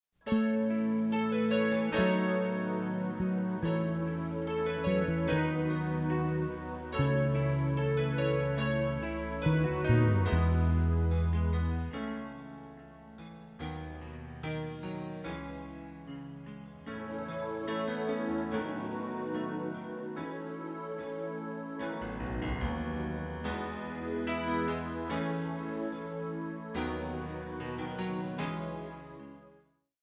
MPEG 1 Layer 3 (Stereo)
Backing track Karaoke
Pop, 1990s